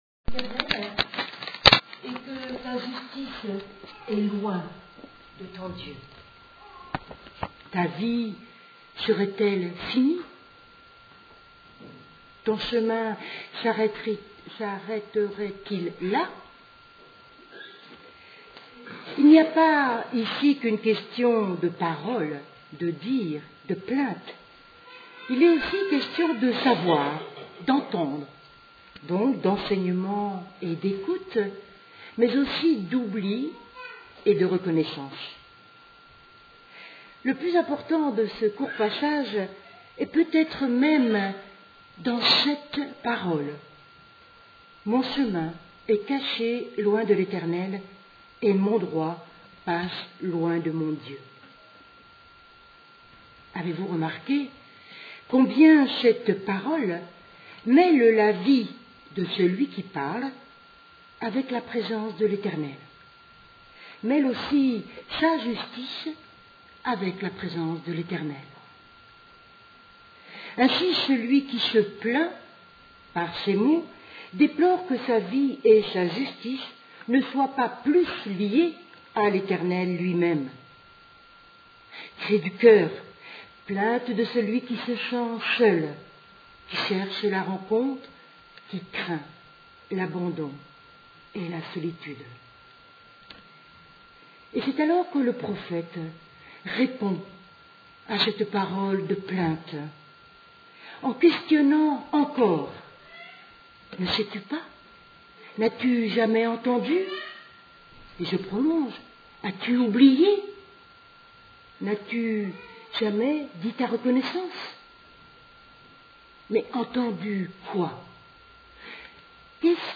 Podcasts prédications